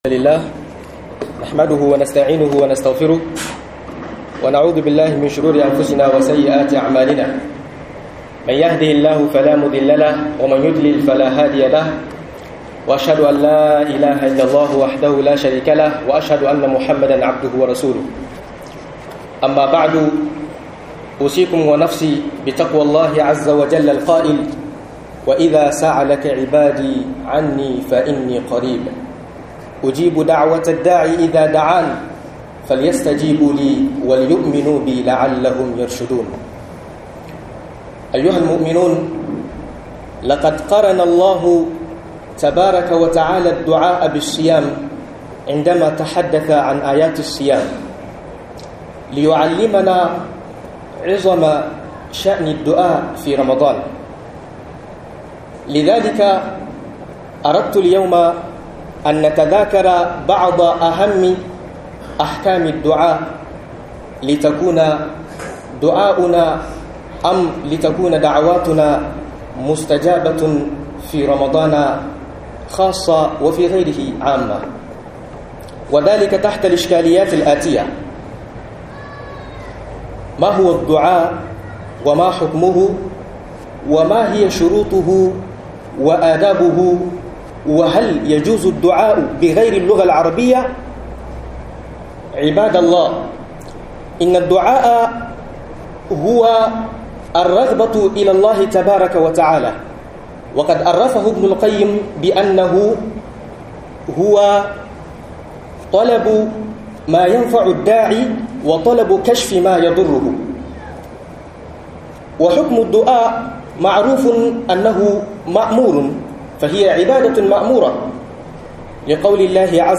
Taya za'a karba maka adduar ka a Ramdan - MUHADARA